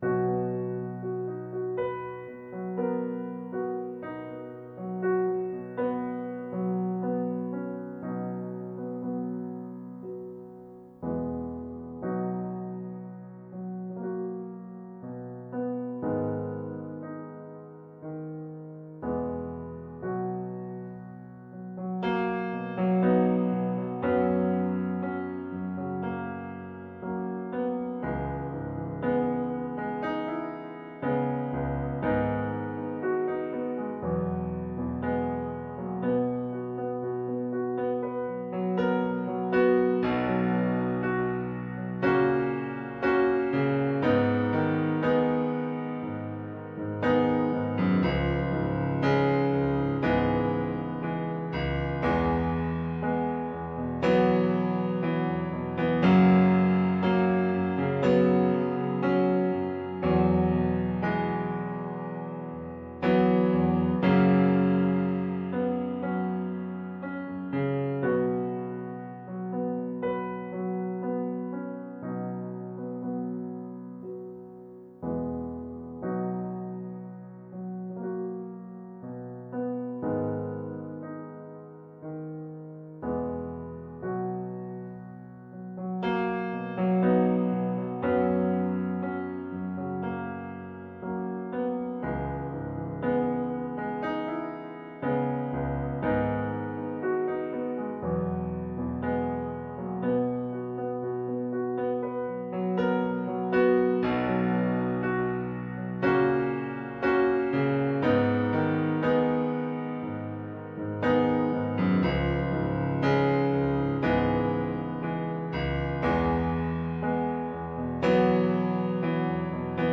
Sunday Morning Piano
This beautiful Sunday morning, I share with you a lovely piano instrumental from one of my original songs “I Am Here”.
i-am-here-piano-with-reverb.wav